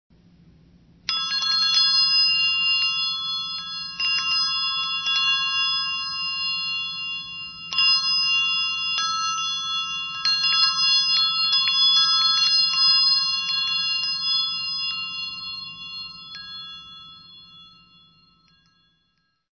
Woodstock Chimes, Bells and Gongs